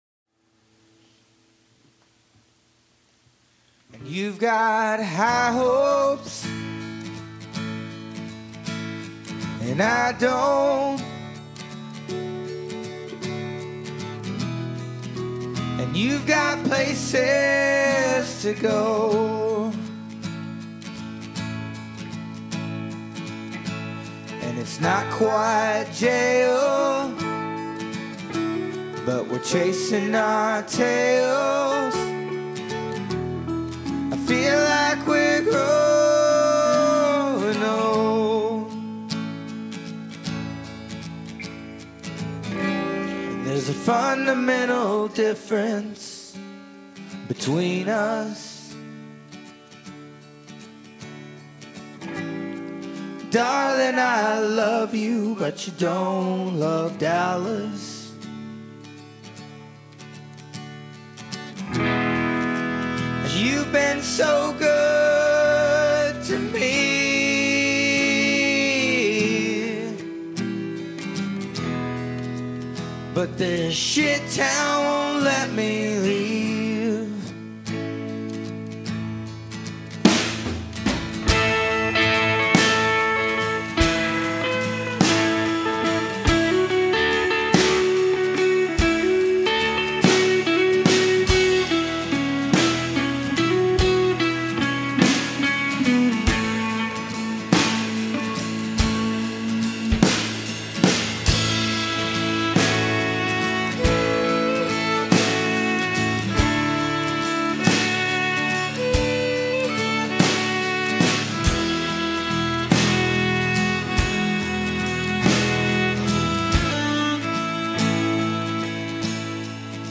I just recorded this last weekend with an 8 track. It was a bit of a challenge trying to get the full drum kit, an acoustic guitar, an electric, 3 vocalists, a violinist, and to top it all off... they wanted to do it live.